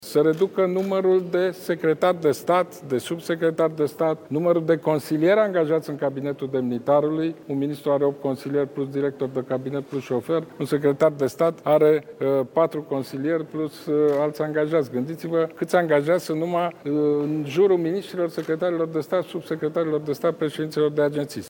De mulți dintre ei instituțiile se pot lipsi, spune acum fostul premier Ludovic Orban.